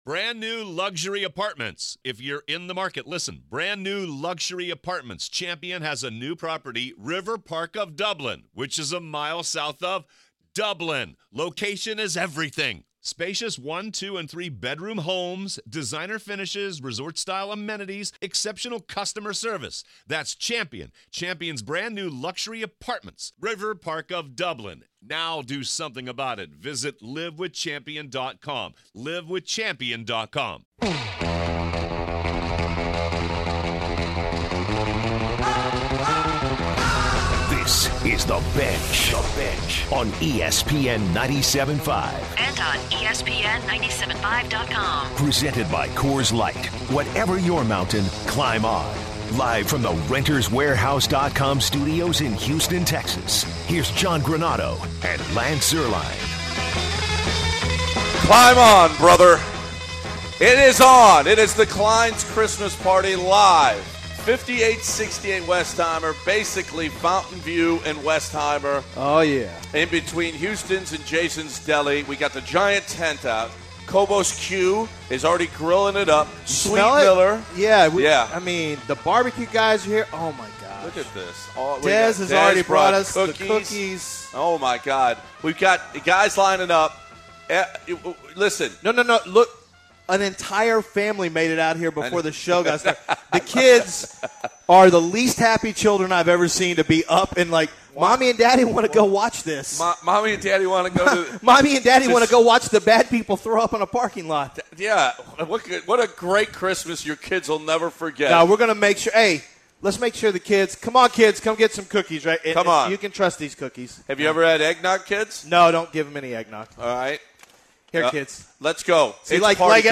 Live from Klein’s Fine Jewelers